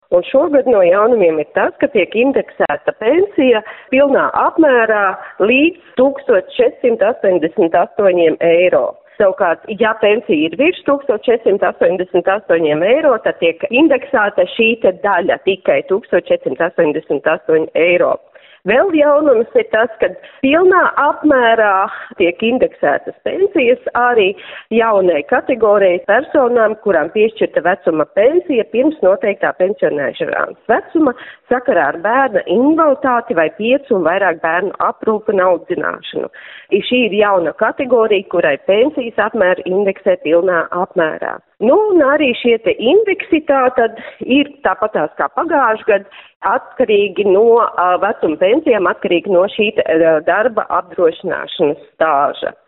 intervijā Skonto mediju grupai pastāstīja Valsts sociālās apdrošināšanas aģentūras